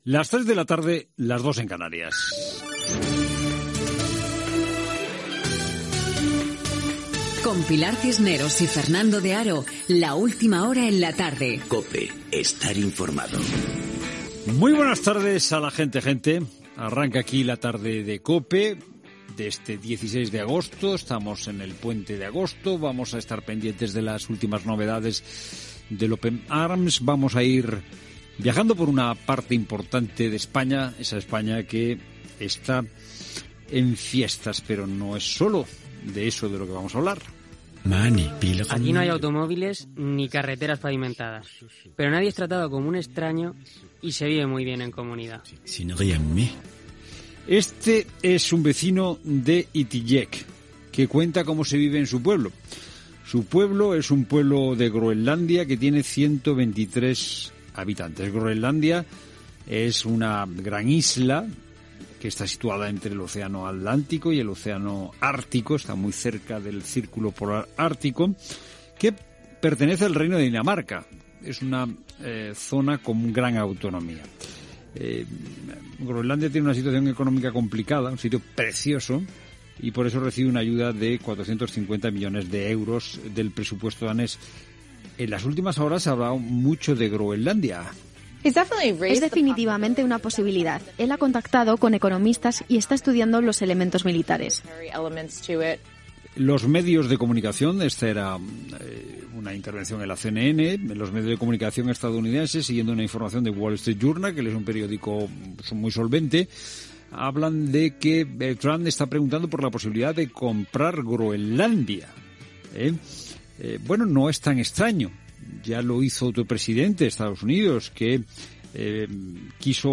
Careta, inici del programa amb comentaris sobre la compra de Groenlàndia per part dels Estats Units.
Entreteniment
FM